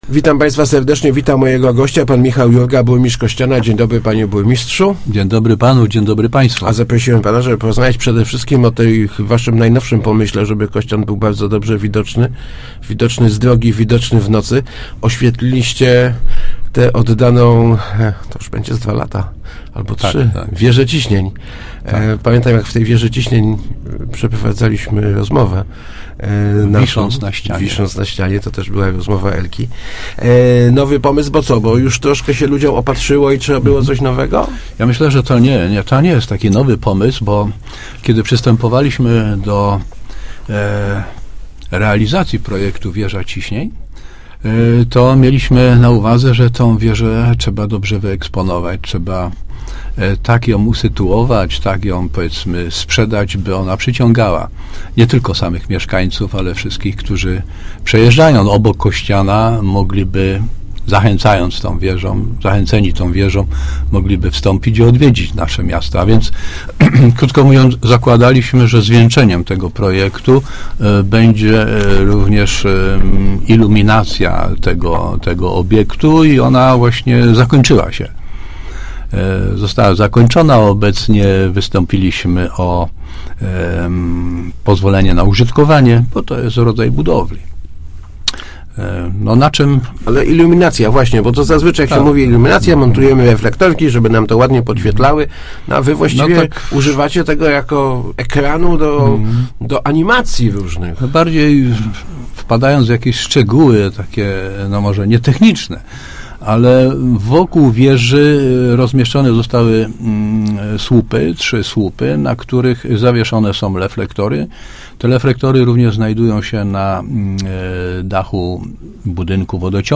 -Tworz� j� 32 reflektory, które daj� mo�liwo�� tworzenia setek kompozycji kolorystycznych - mówi� w Rozmowach Elki burmistrz Micha� Jurga.